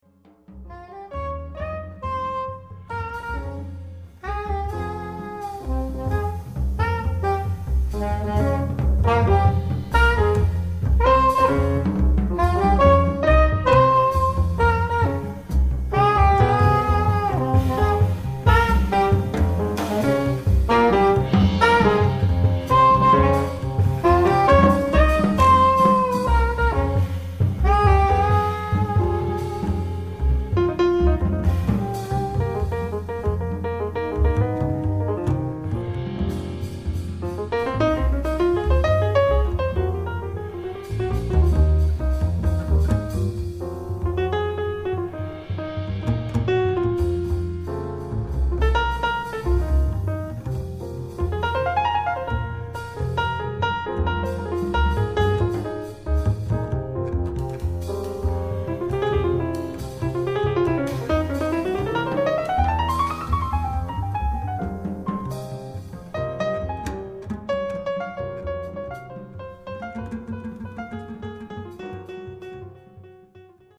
piano
alto & soprano sax
bass
drums